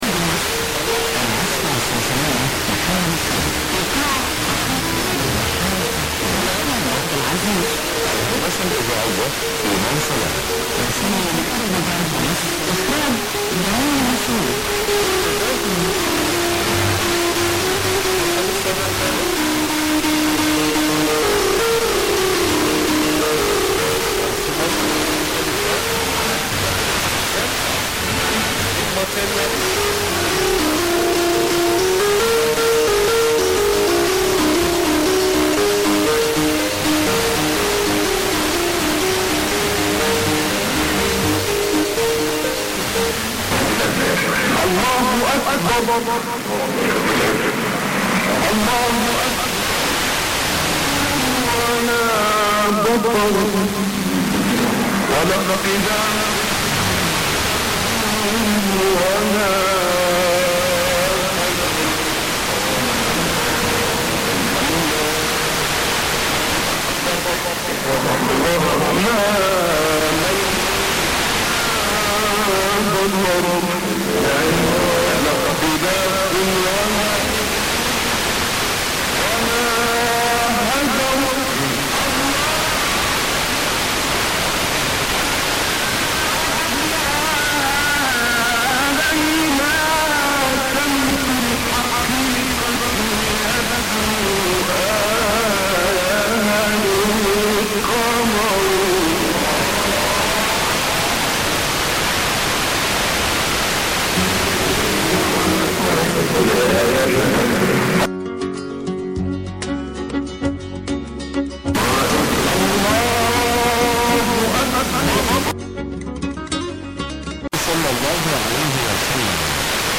Ez szerintem az Al Rasheed, nagyon iraki akcentusnak tűnik, és ez csak épp imaidőben volt. Nem tűnik "iszlamista" adásnak.